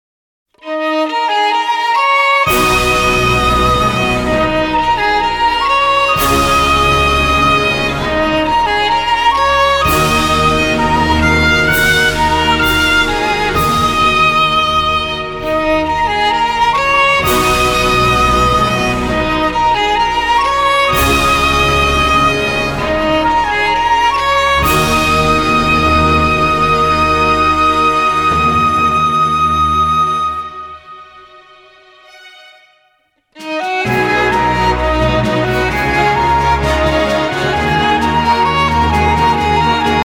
ソロヴァイオリン
ソロフルート